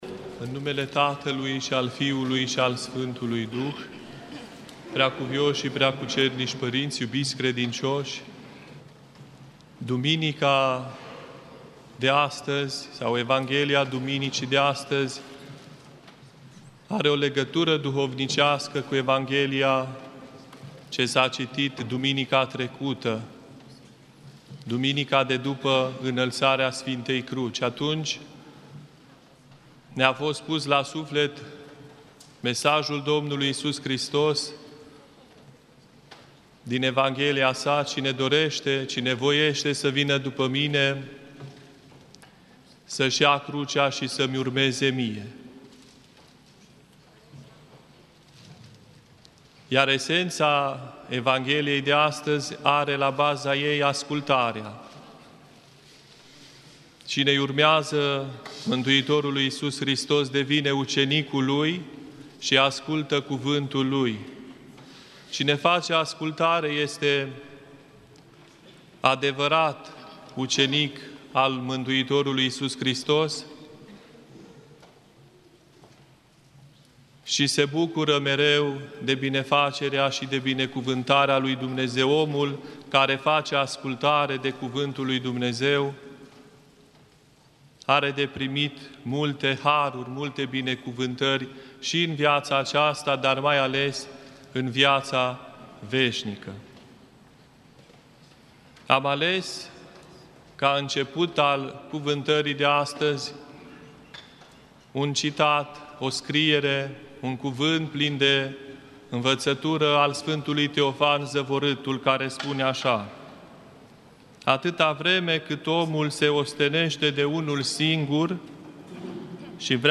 În predica din Duminica a 18-a după Rusalii
în Catedrala Mitropolitană din Cluj-Napoca despre importanța ascultării.